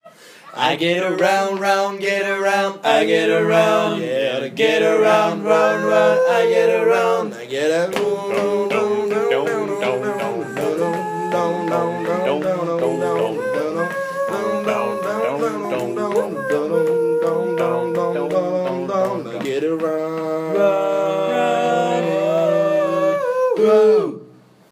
A capella, le retour